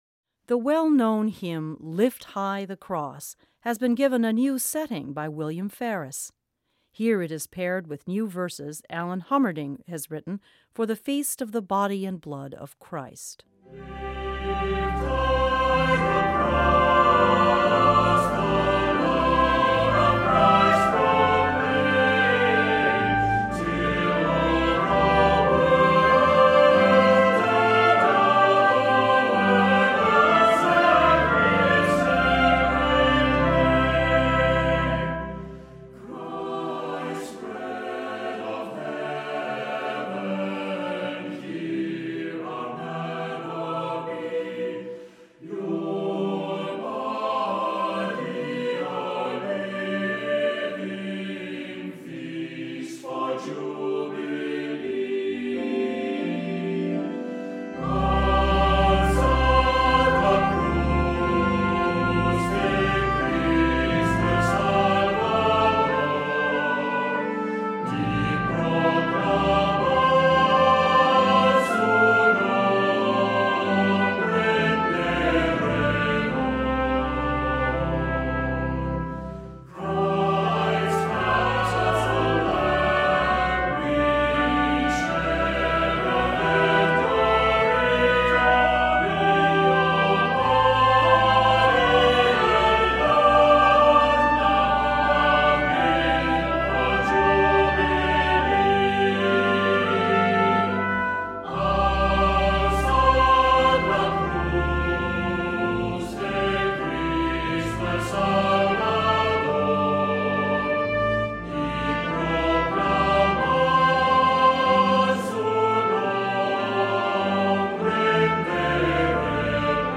Voicing: Assembly